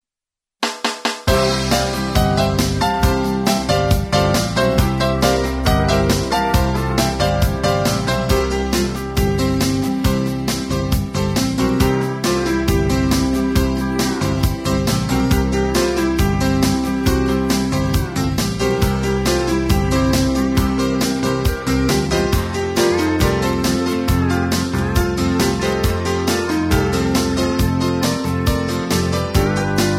Singing Call